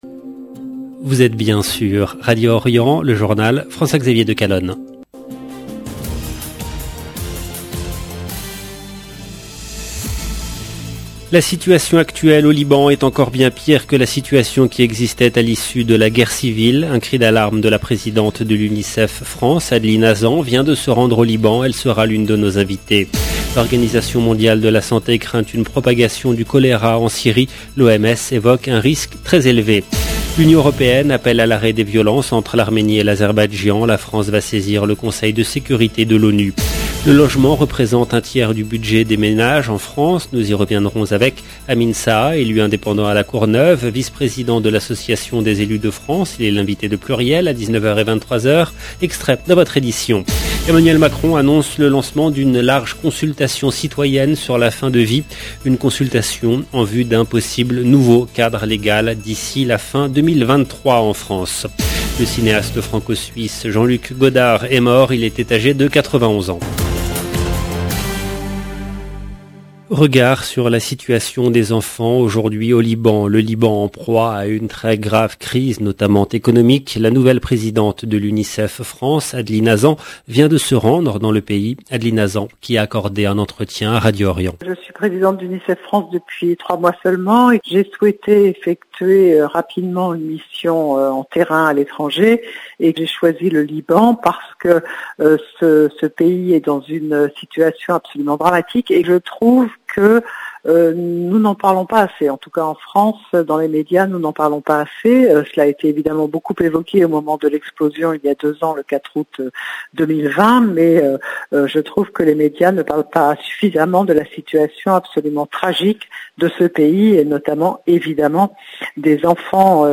Journal présenté par